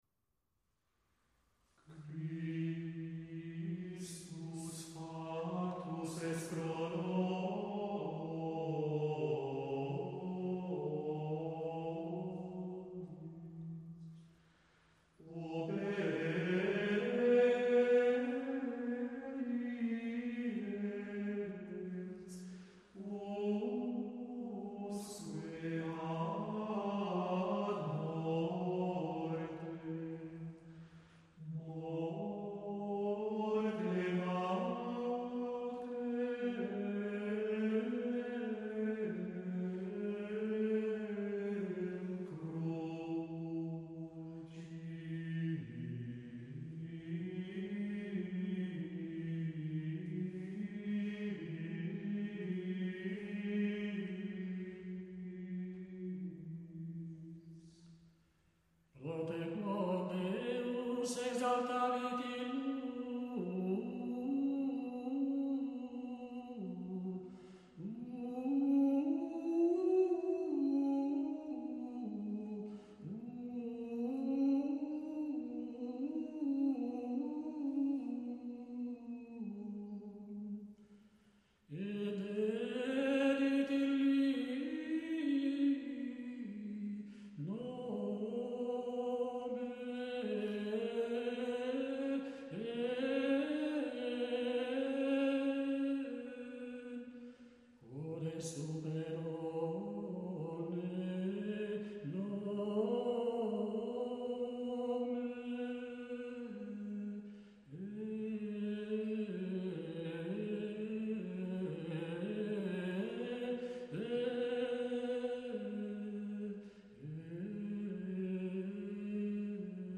Graduale
È fra i canti più ornati e melismatici della messa (da qui il nome all'intero libro che raccoglie tutti i canti della messa). Usa un ambitus piuttosto ampio e in genere il versetto è in posizione più acuta del responsum.